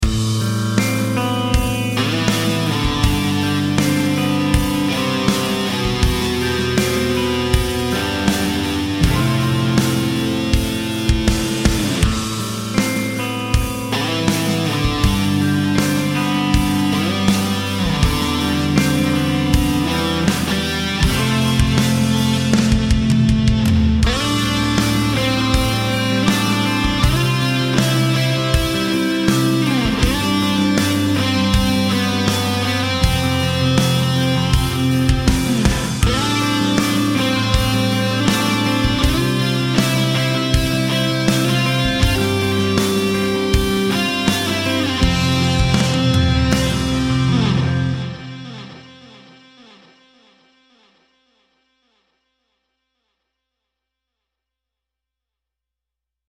There’s one clean and one lead guitar, each of which we will process individually:
80s-Guitars-DDD_DRY.mp3